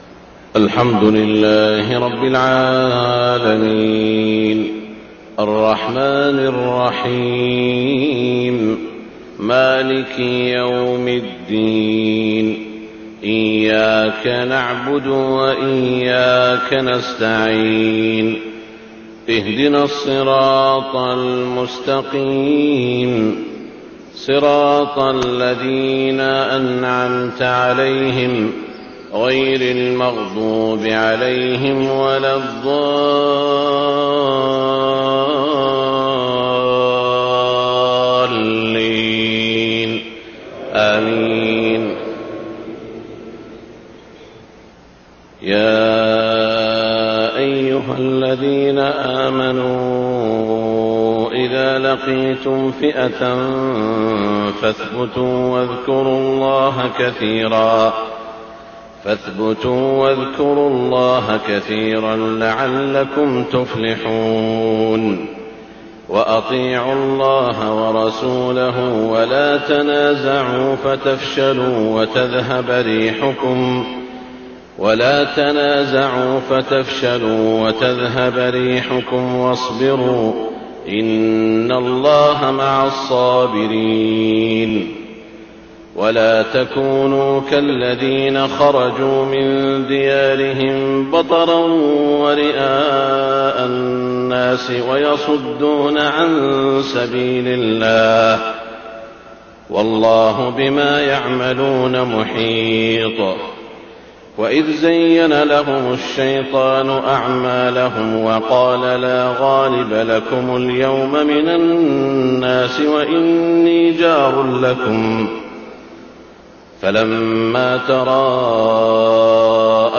صلاة الفجر 7 شعبان 1430هـ من سورة الأنفال > 1430 🕋 > الفروض - تلاوات الحرمين